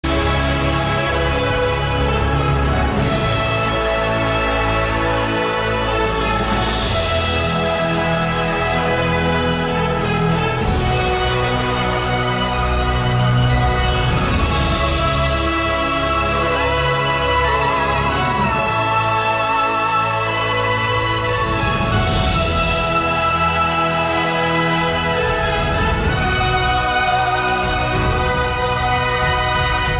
A Powerful Fusion of Classic and Modern
dynamic remix music